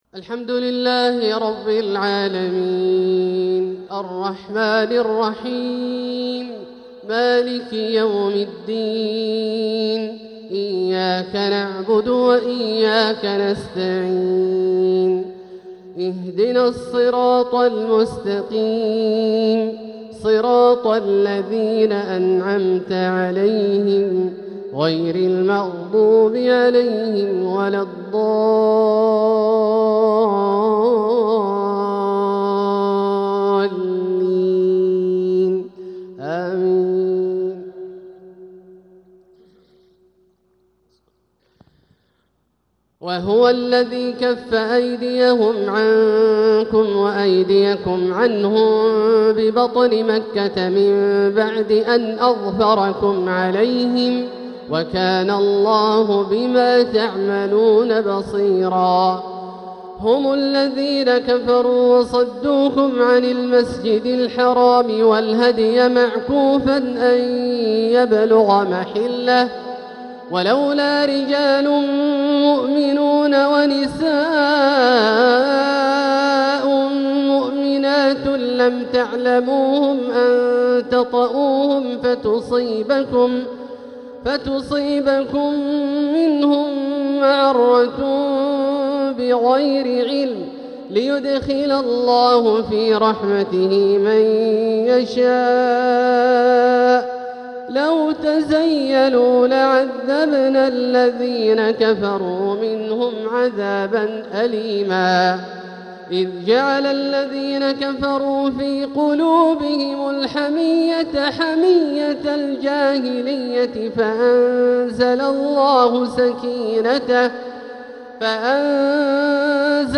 تلاوة لخواتيم سورة الفتح 24-29 | عشاء الأحد 9 صفر 1447هـ > ١٤٤٧هـ > الفروض - تلاوات عبدالله الجهني